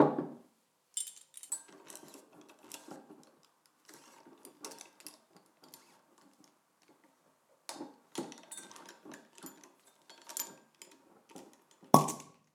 household
Corkscrew Wine Bottle Open 2